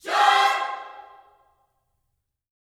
JOY CMAJ 5.wav